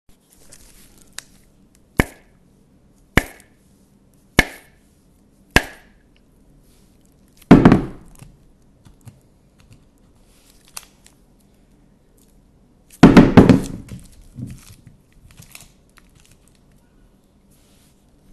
Coconuts hit together then fall onto a wood floor
coconuts fall floor hit impact thud wood sound effect free sound royalty free Memes